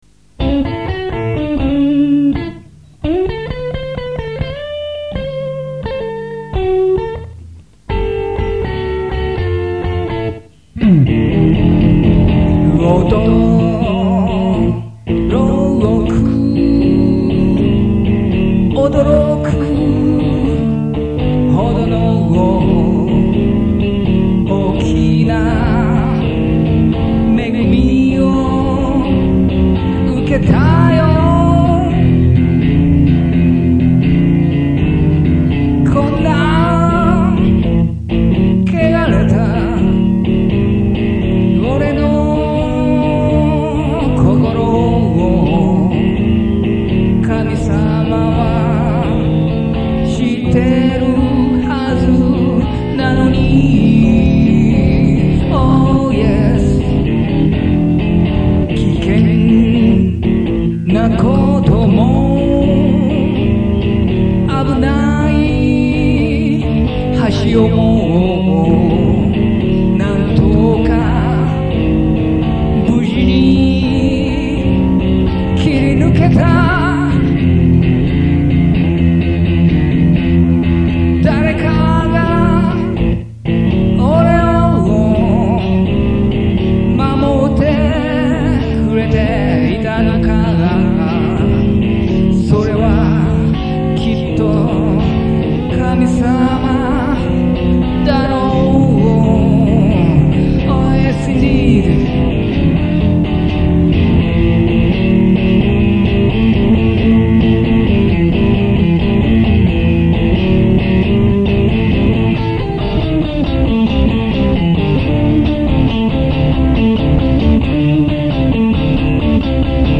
ゴスペルと言えば、大人数で元気よく英語で歌う、そんなスタイルが人気であり、迫力もあっていいのだが、私の場合、一人で歌うので、寂しい。
得意のブルースロックスタイルで弾き語る。オーバーダビング一切なしの一発録り。